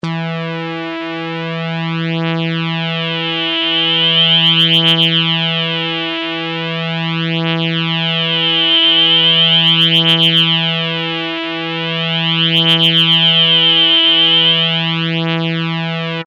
TRI+Resonance.mp3